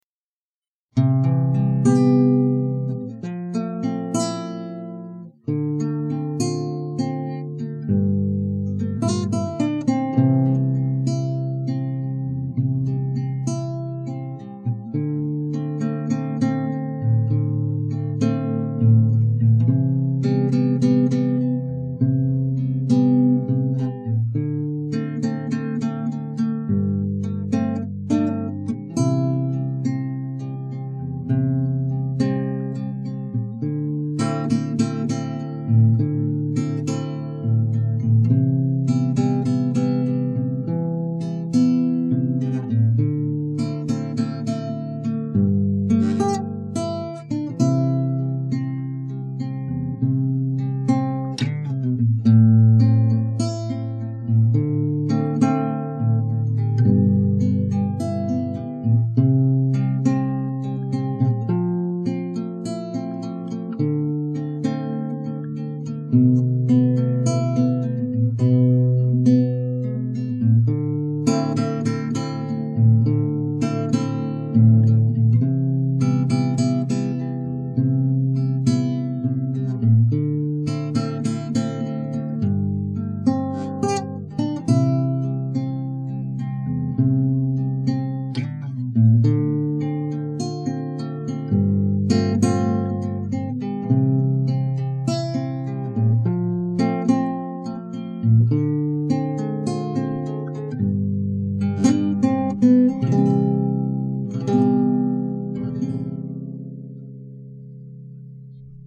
Романтическая баллада
до-мажор